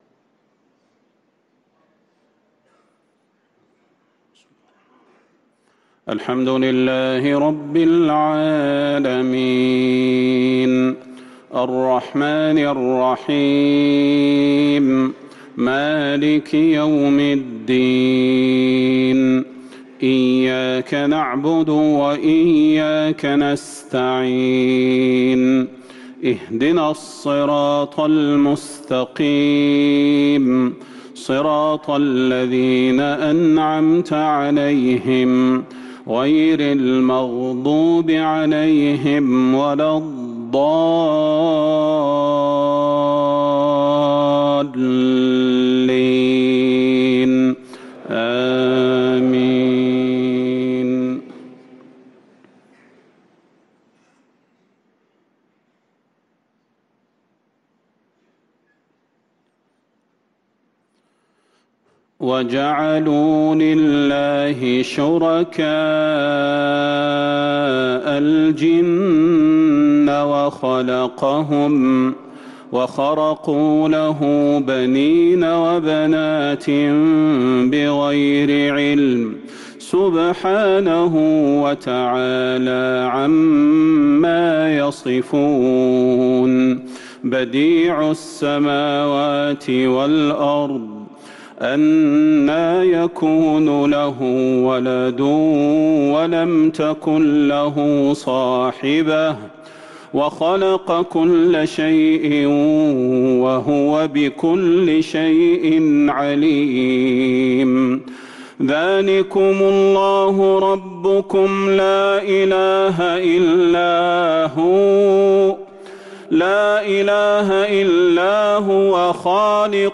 صلاة العشاء للقارئ صلاح البدير 28 ربيع الأول 1445 هـ
تِلَاوَات الْحَرَمَيْن .